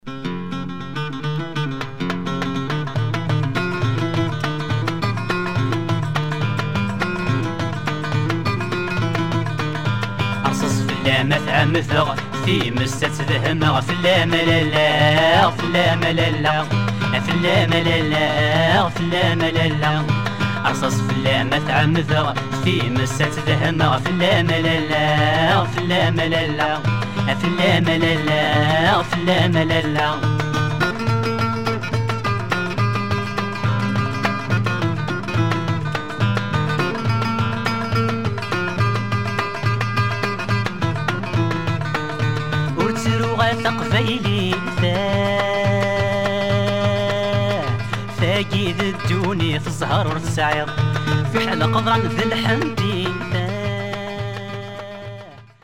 Rare Algerian sounds from Kabylie.